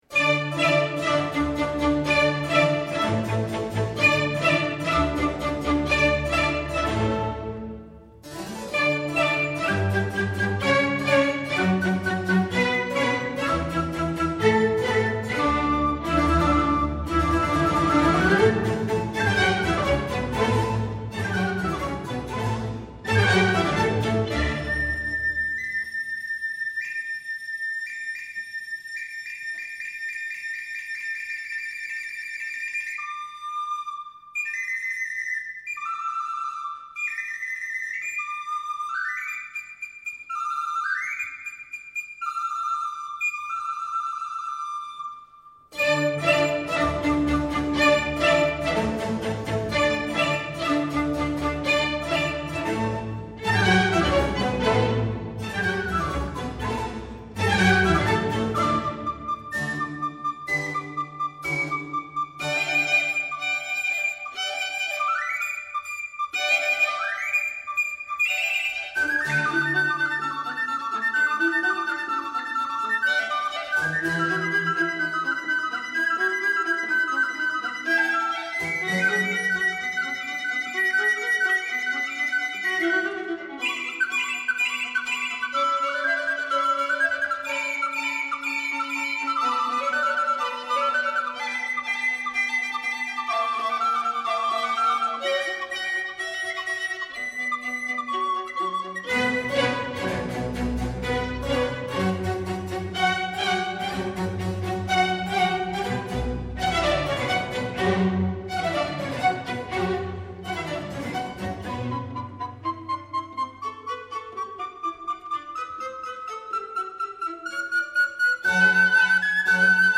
harpsichord
Allegro